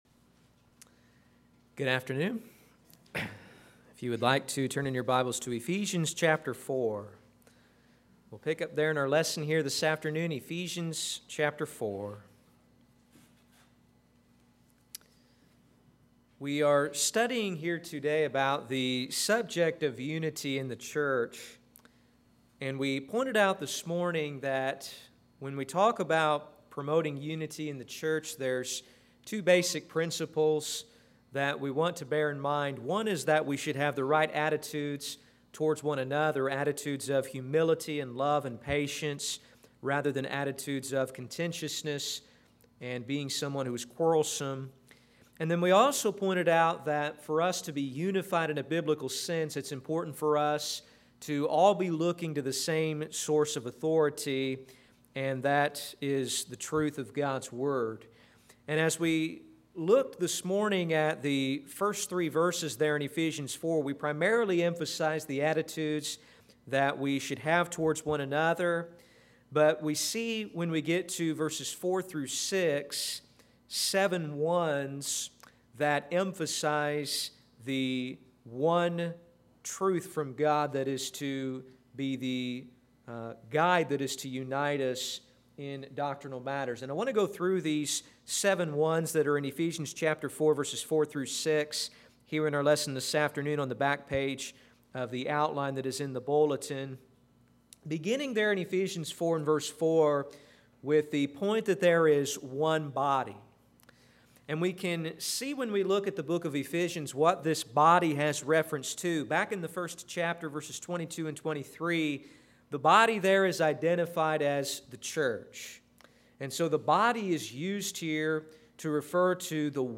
Sermons - Olney Church of Christ
Service: Sunday AM